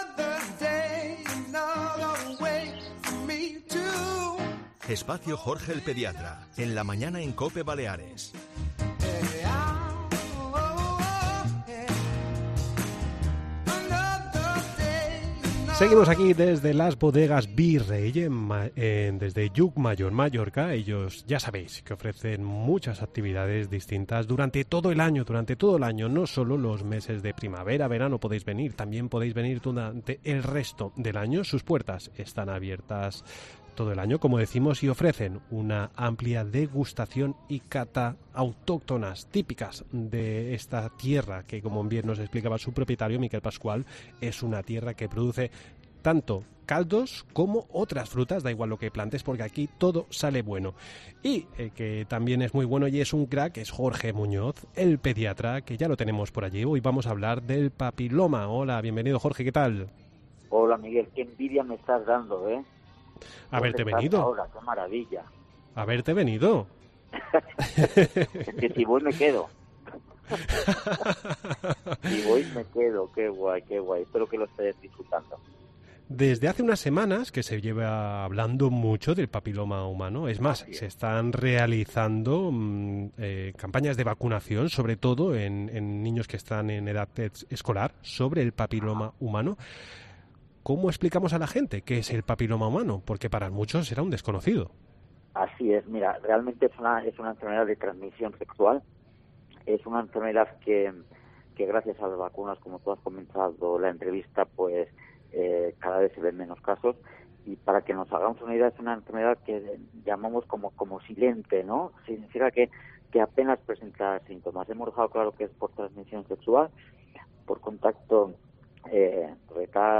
E ntrevista en La Mañana en COPE Más Mallorca, martes 21 de marzo de 2023.